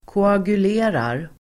Uttal: [koagul'e:rar]
koagulerar.mp3